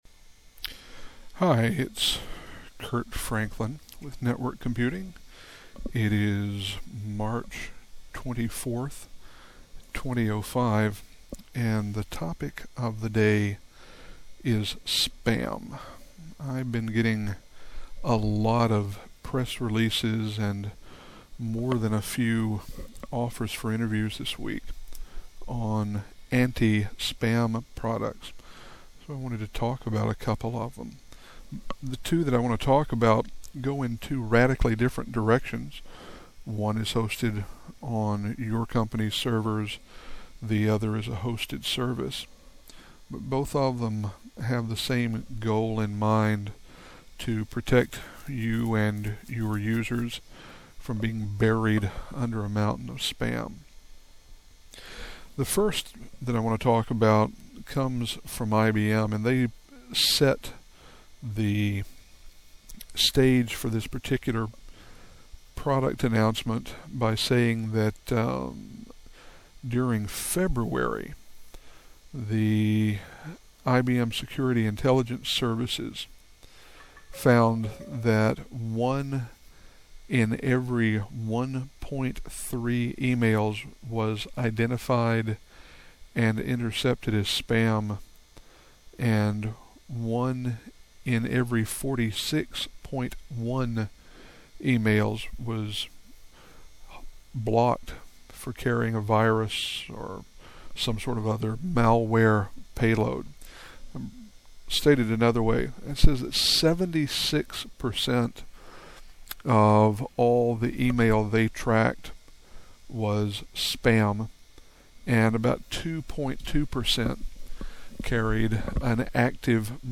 Now, the first few podcasts are going to be awfully simple--me and a microphone, as I try to screen out the noise of the switch that sits behind my head in the office.